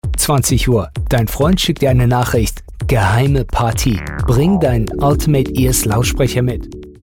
Warm and comforting, friendly and vibrant; with the easy ability to be intimidating, strong or menacing.
Radio Ad
announcer, cool, foreign-language, german, promo